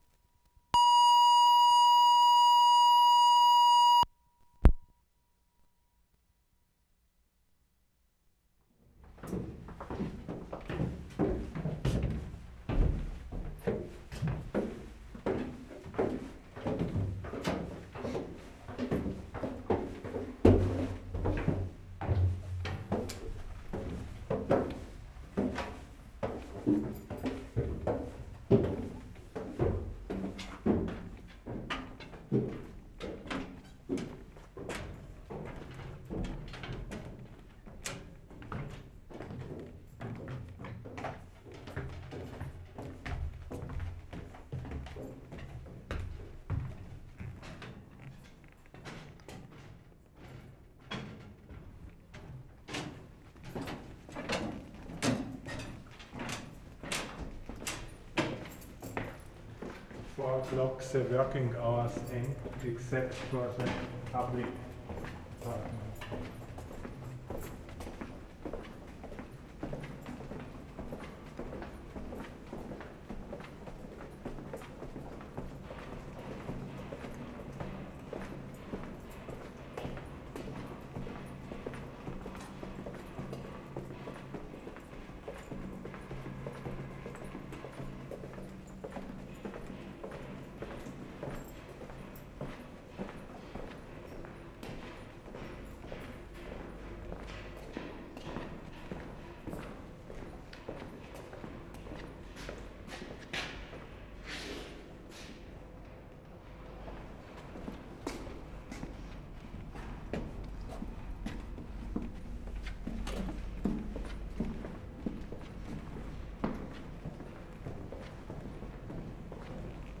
WORLD SOUNDSCAPE PROJECT TAPE LIBRARY
Vienna/ Nussdorf March 19/75
NATIONAL LIBRARY TOUR OF STACKS
mark * closing gate in tunnel. [2:57]
1. Walking through tunnels, up and down stairs to the new reading room. Good sequence of footsteps (3 people) in different ambiences. Entering reading room: from busy to muted, studious ambience.